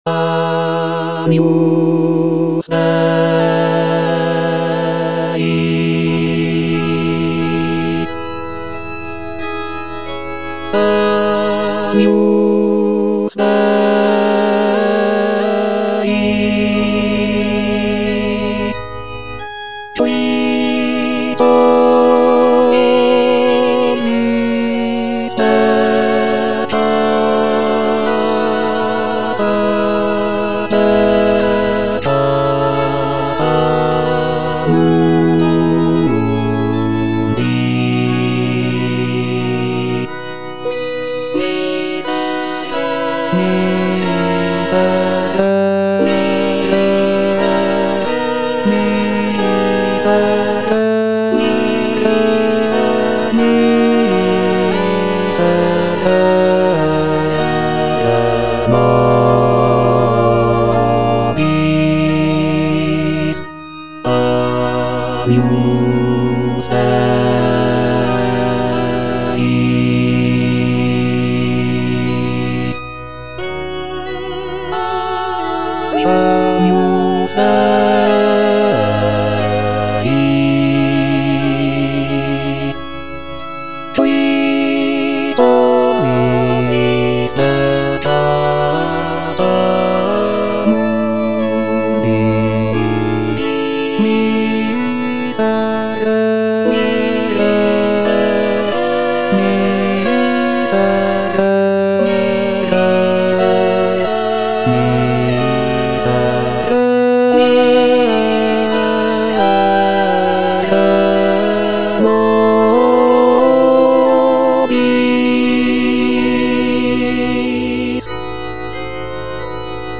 J.G. RHEINBERGER - MISSA MISERICORDIAS DOMINI OP.192 Agnus Dei - Bass (Emphasised voice and other voices) Ads stop: auto-stop